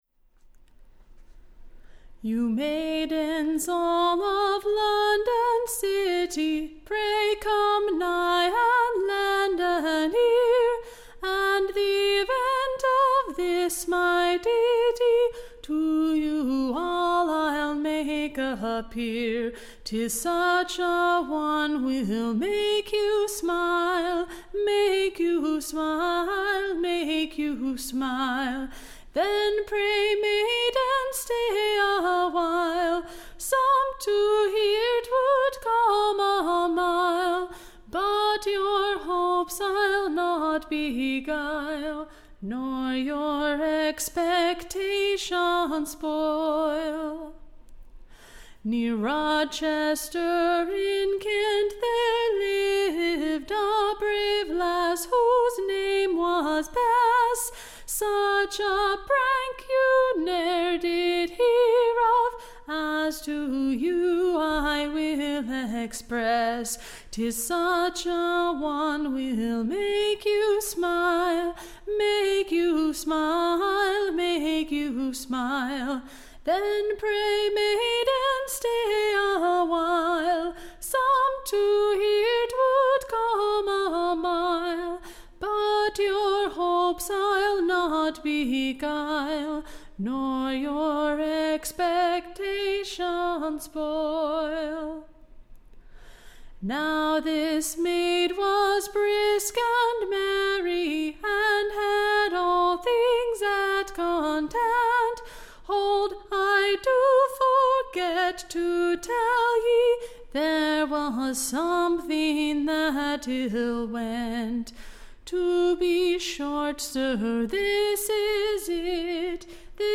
Recording Information Ballad Title DICK the Plow-man / Turn'd Doctor.
/ Shewing how a Country Maid in Kent fell in love with her Fellow-Servant Dick the Plow- / man, aud how he Cured her of her Sick Distemper, &c. Tune Imprint To the Tune of, O Mother Roger, &c. Standard Tune Title O Mother!